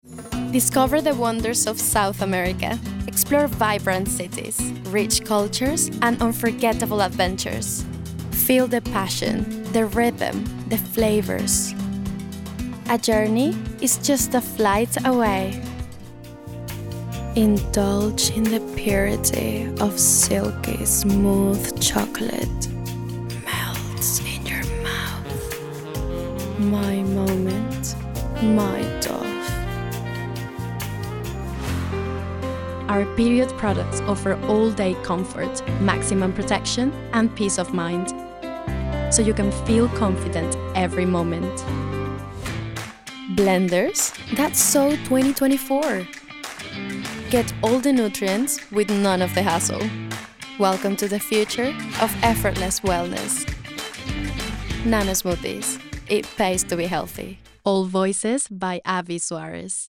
Commercial Voice Reel